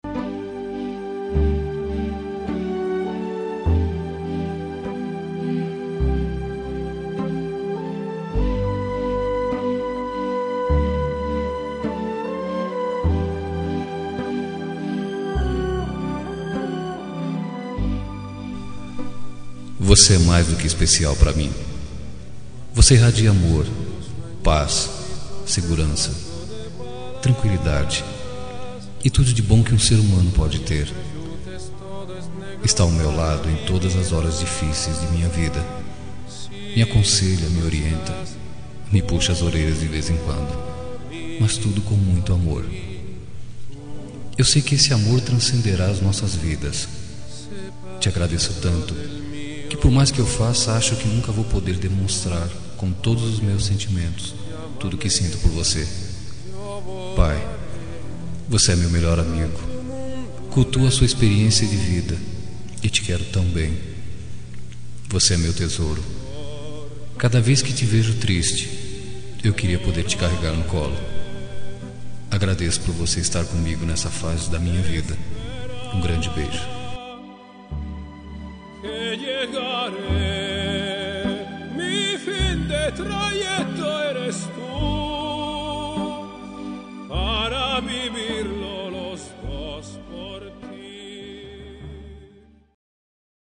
Homenagem para Filha – Voz Masculina – Cód: 323 – Linda